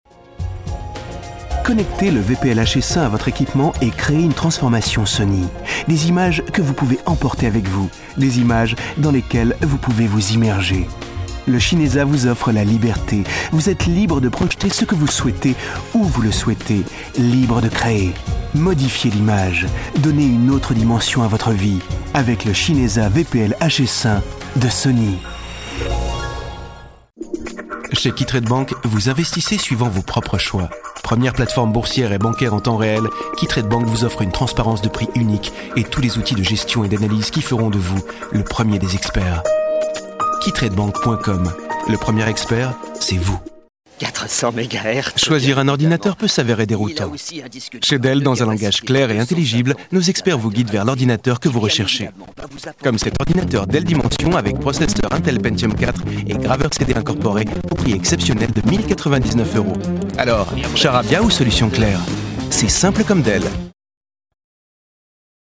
Sprecher französisch
Kein Dialekt
Sprechprobe: Werbung (Muttersprache):
french voice over talent